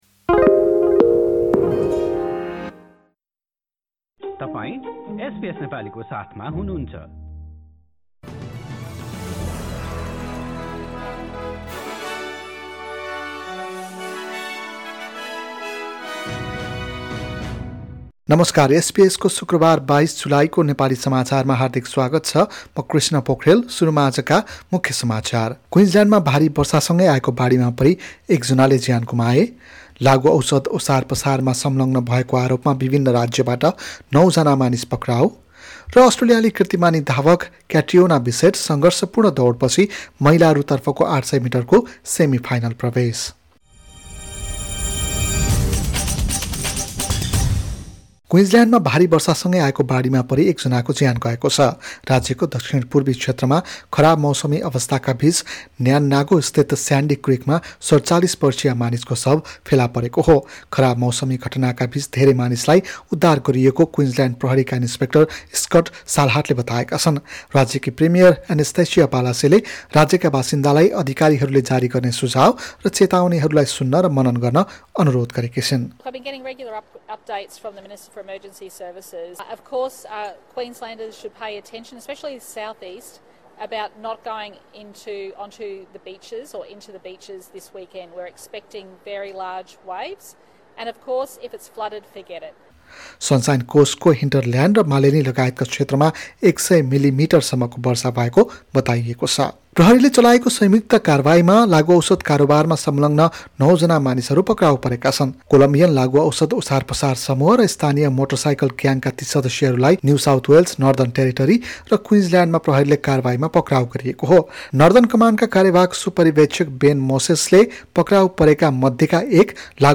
एसबीएस नेपाली अस्ट्रेलिया समाचार: शुक्रबार २२ जुलाई २०२२